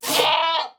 Minecraft Version Minecraft Version 1.21.5 Latest Release | Latest Snapshot 1.21.5 / assets / minecraft / sounds / mob / goat / screaming_milk2.ogg Compare With Compare With Latest Release | Latest Snapshot
screaming_milk2.ogg